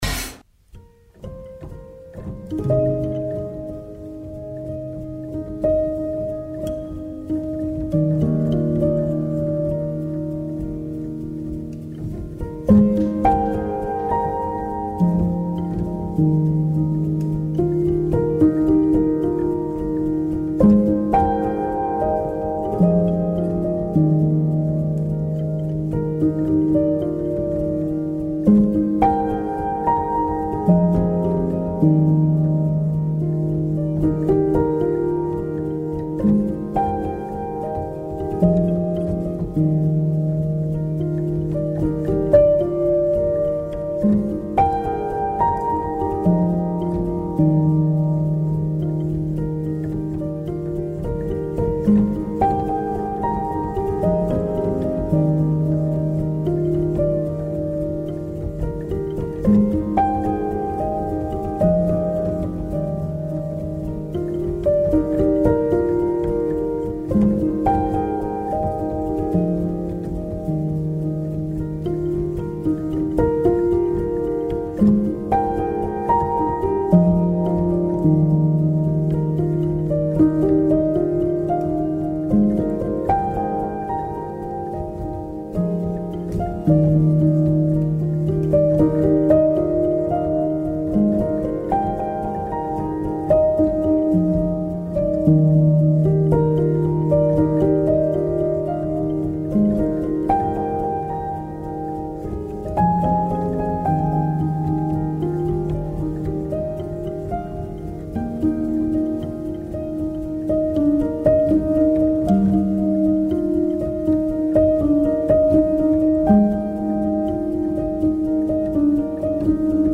Remix, rework, il testo espanso - In un mondo musicale lontano (ma nemmeno troppo) dal pop e dal rock, i testi si aprono, le musiche viaggiano e si contaminano in modo infinito e generativo. Scopriamo come alcuni compositori e compositrici lavorano sulla musica per far sì che le note raccontino ancora di più di quanto già facciano.